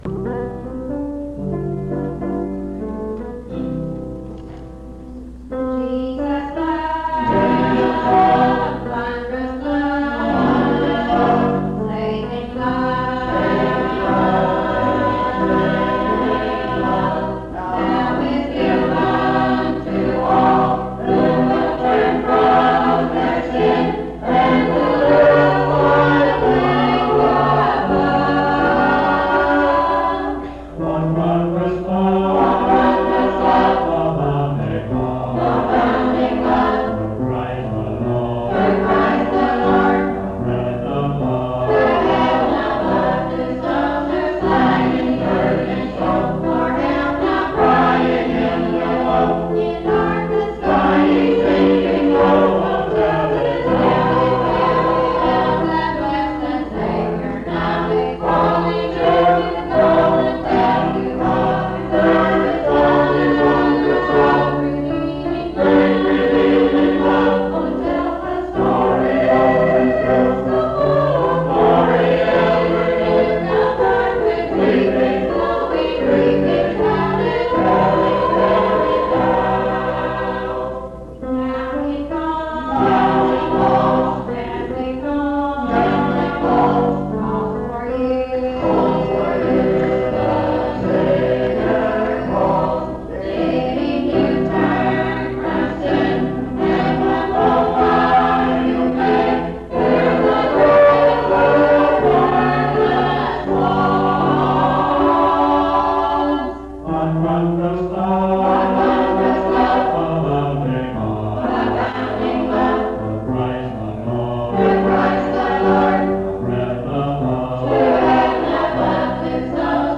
What Wondrous Love Item fd4b215f49899c40be45a748dae4cdd01c203c3a.mp3 Title What Wondrous Love Creator Listed as Congregation, but I think it's the Highland Park Methodist Church Choir Description This recording is from the Monongalia Tri-District Sing. Church of the Bretheren, Morgantown, Monongalia County, WV, track 138Q.